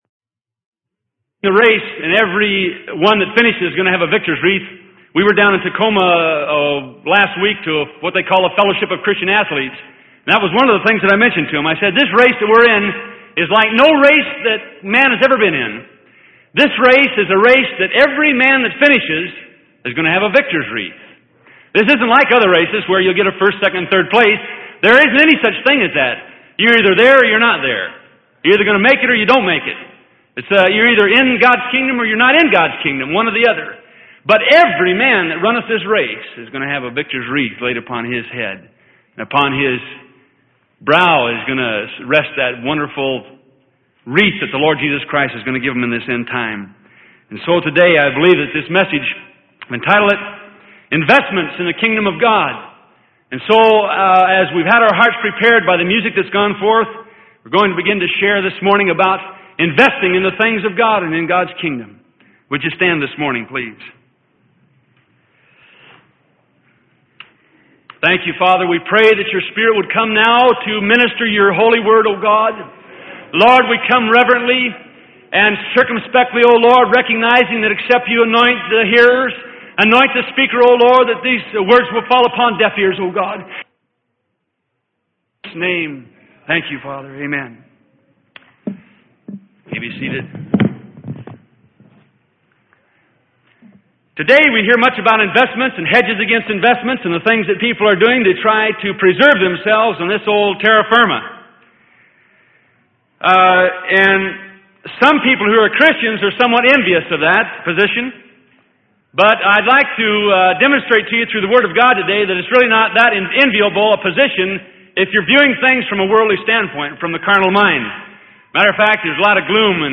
Sermon: Investments in the Kingdom of God - Freely Given Online Library